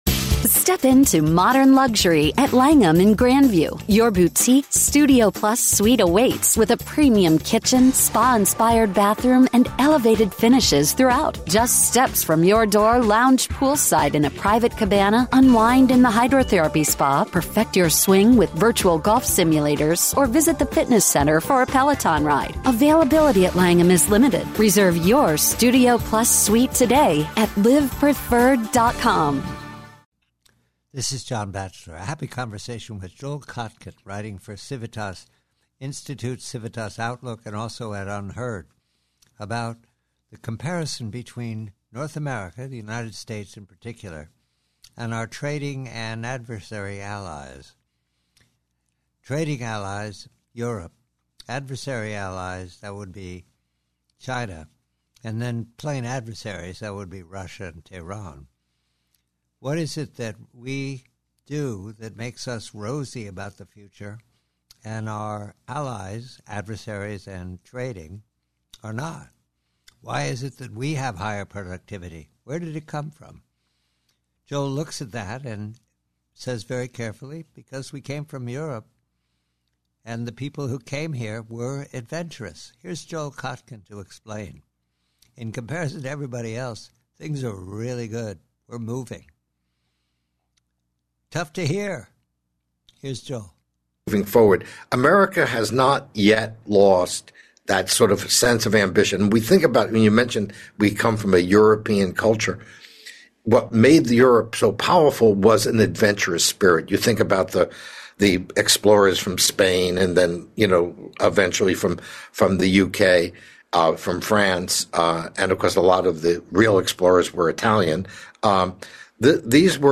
Preview: Conversation with Joel Kotkin re the American adventurer and the European stay at home.